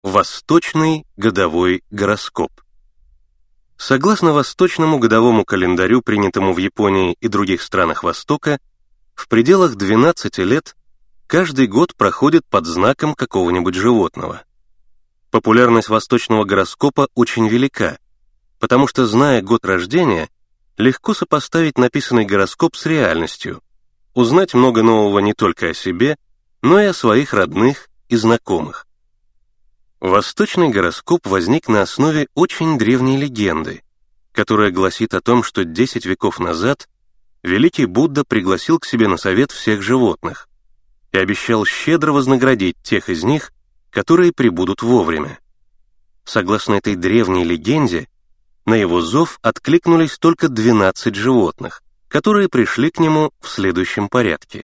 Аудиокнига Восточный гороскоп | Библиотека аудиокниг